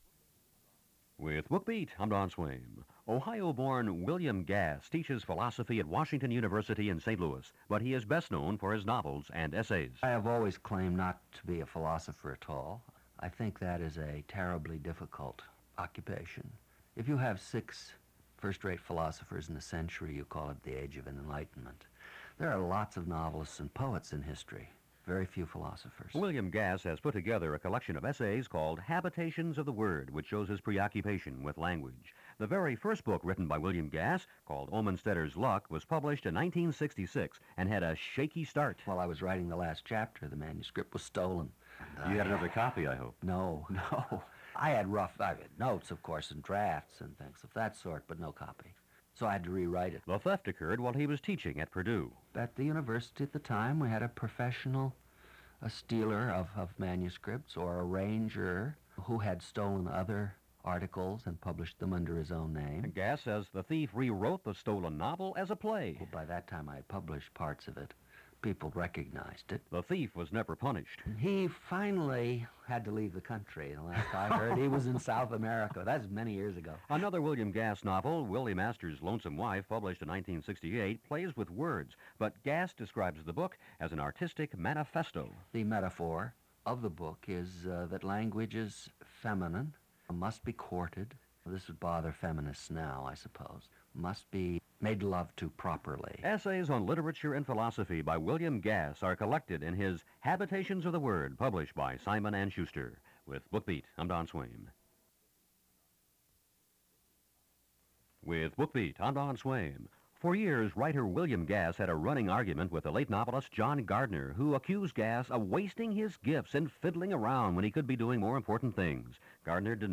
These short radio spots aired on WCBS/CBS Radio in April 1985 to promote the publication of Habitations of the Word. In clips taken from a longer interview, Gass talks about not considering himself a philosopher, the theft of his Omensetter’s Luck draft, debating John Gardner, the novel as metaphor for life, and more.
Audio Cassette Tape